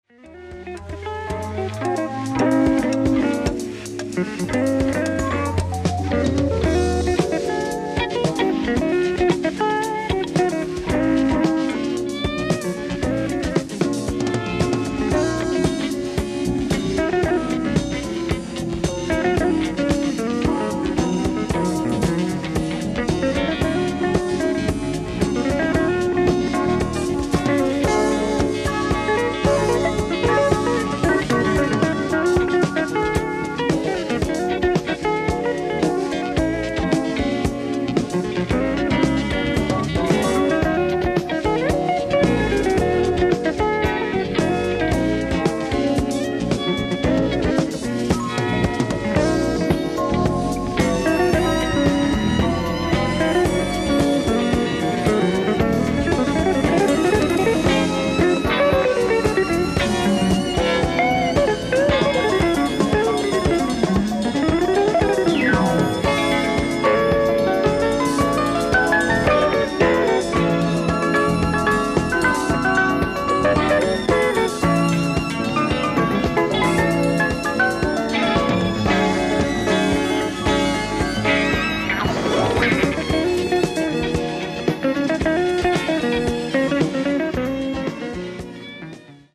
ディスク１：ライブ・アット・スペクトラム、フィラデルフィア 08/25/1979